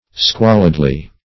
Search Result for " squalidly" : Wordnet 3.0 ADVERB (1) 1. in a sordid or squalid way ; [syn: sordidly , squalidly ] The Collaborative International Dictionary of English v.0.48: Squalidly \Squal"id*ly\ (skw[o^]l"[i^]d*l[y^]), adv.